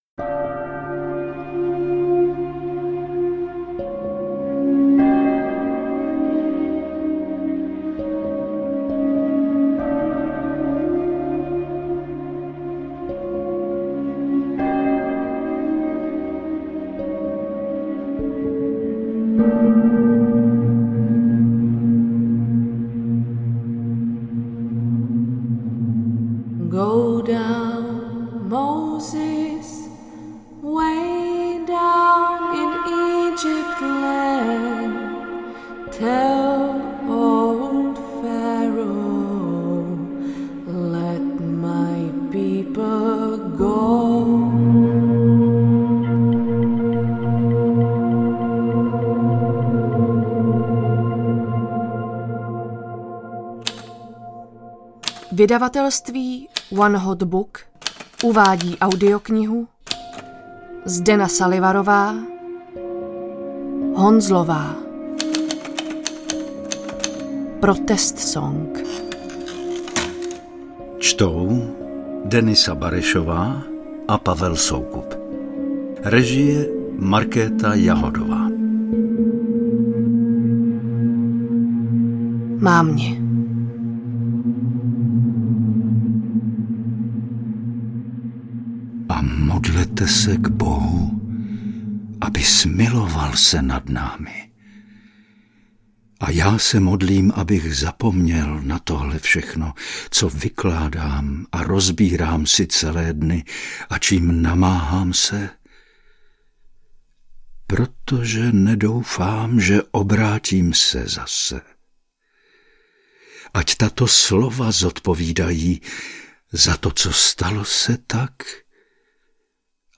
AudioKniha ke stažení, 31 x mp3, délka 10 hod. 11 min., velikost 552,0 MB, česky